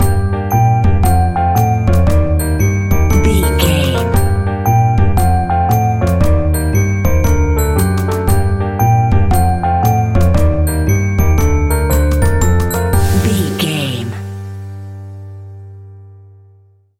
Aeolian/Minor
scary
ominous
dark
haunting
eerie
double bass
electric organ
piano
drums
electric piano
spooky
horror music